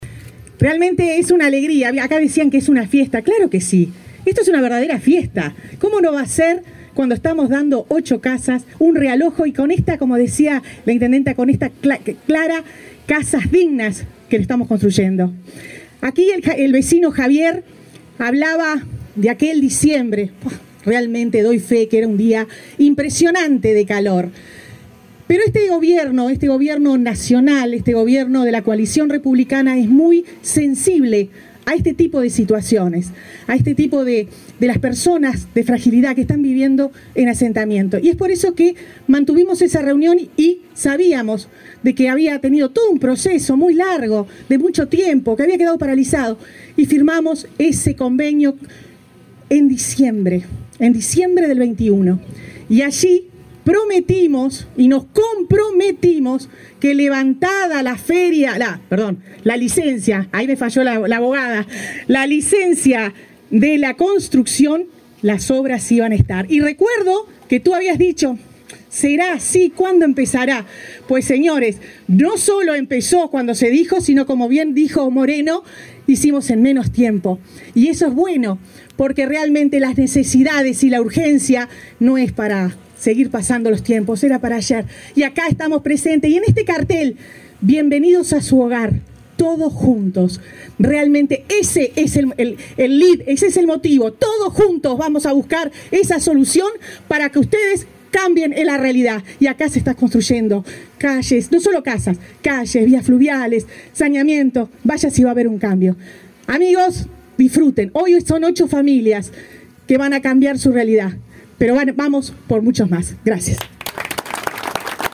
Palabras de la ministra de Vivienda, Irene Moreira
La ministra de Vivienda, Irene Moreira, encabezó la inauguración de casas en el barrio Campichuelo e Itapé, en Montevideo.